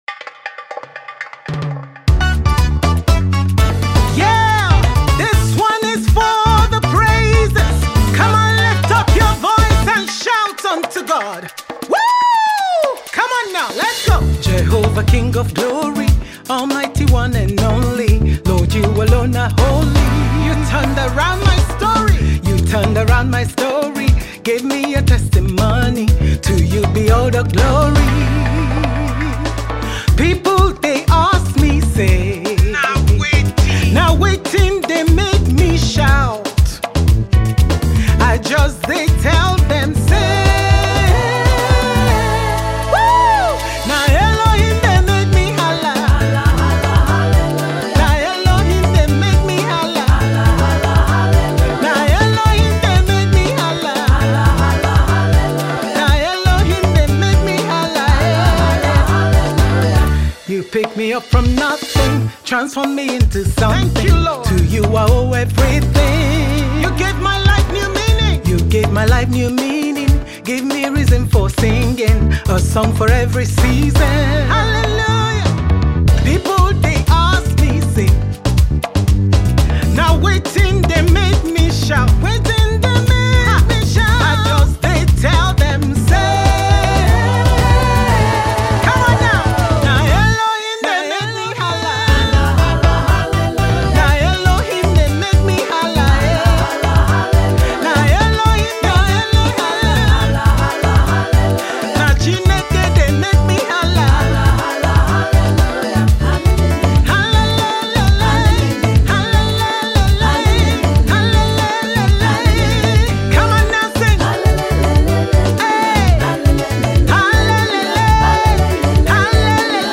Afro-Gospel